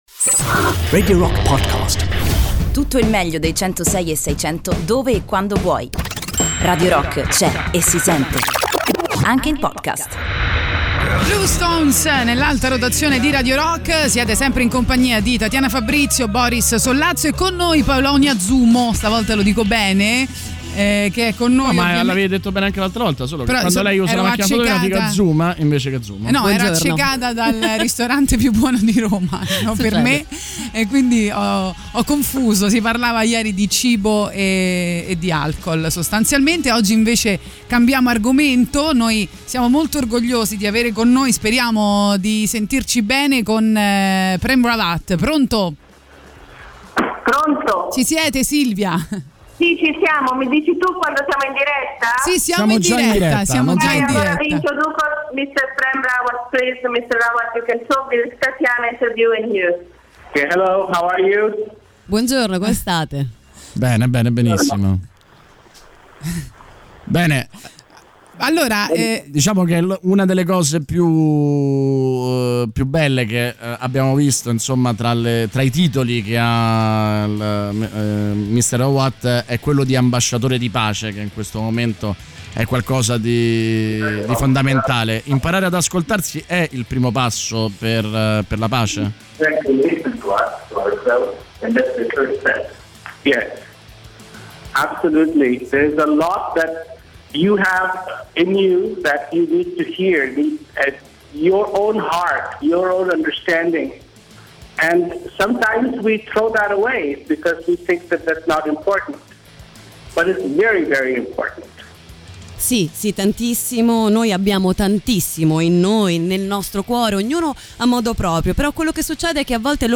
Intervista: Prem Rawat (24-09-20)
in collegamento telefonico con Prem Rawat durante GAGARIN